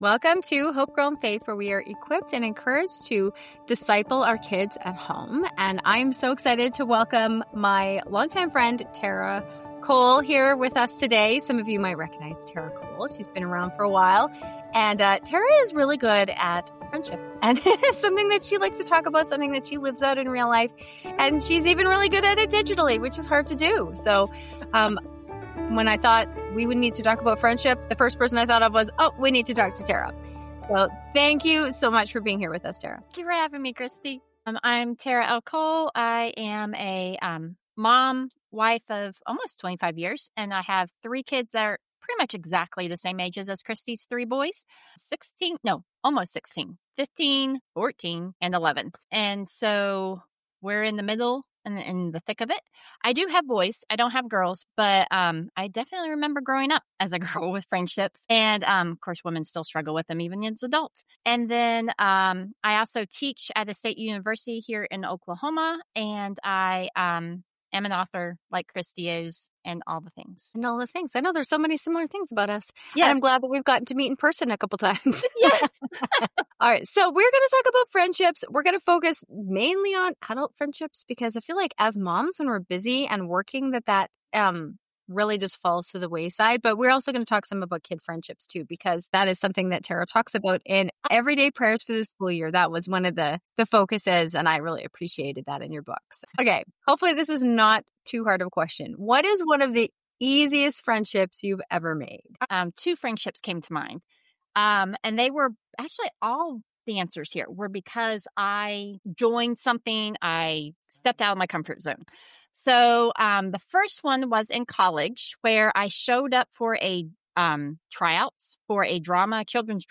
In this heartfelt conversation